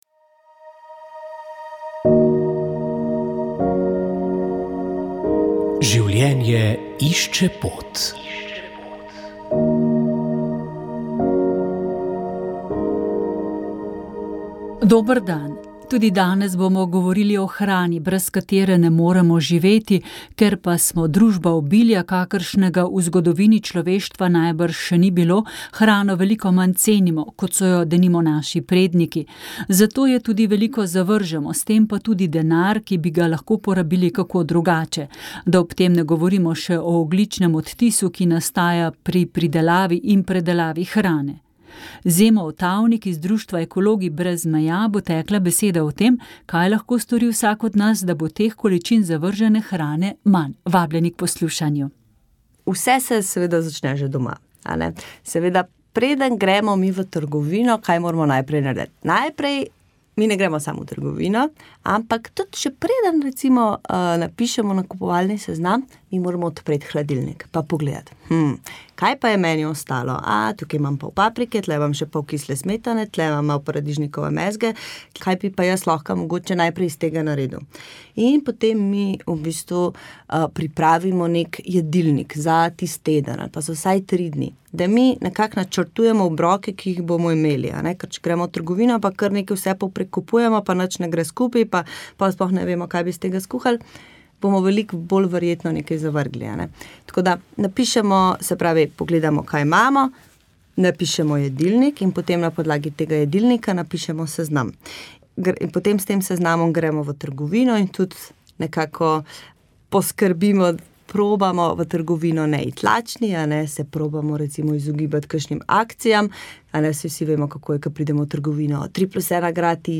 Rožni venec
Molile so redovnice - Hčere krščanske ljubezni (Usmiljenke).